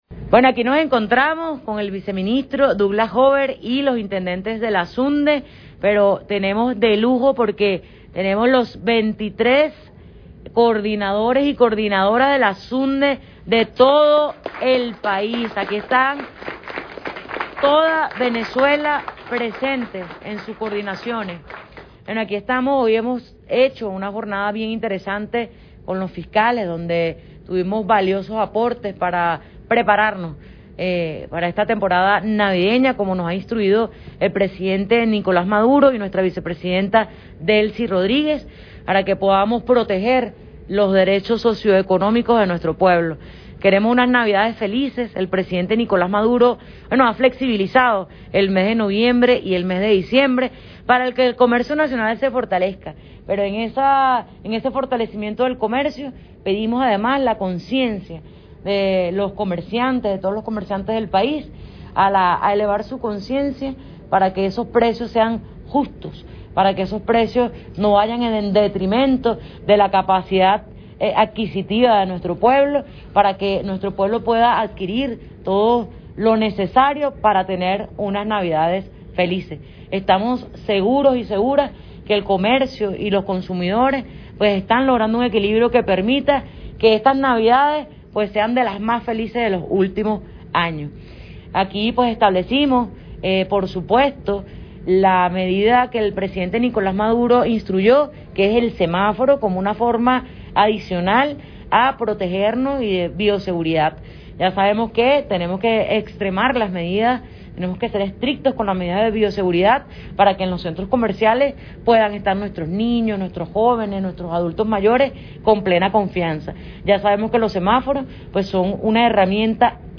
Si deseas escuchas las declaraciones de la Ministra Álvarez a los medios de comunicación puedes hacerlo acá